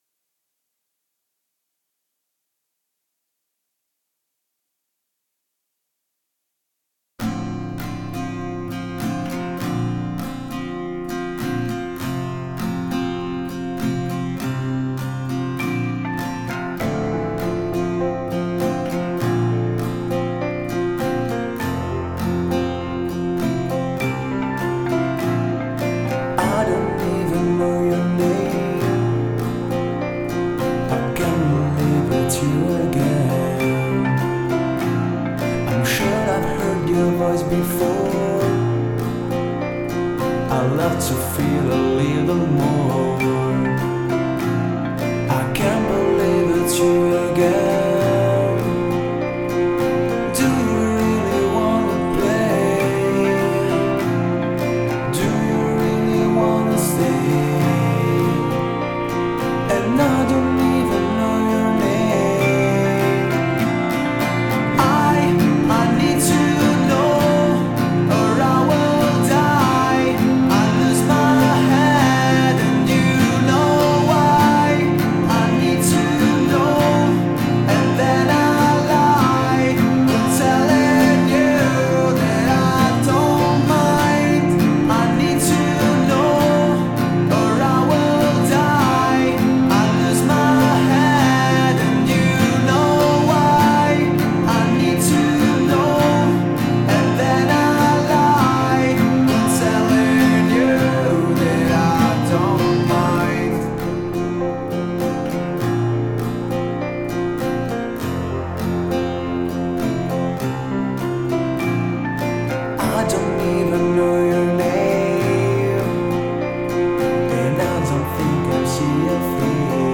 Pop/Rock-Band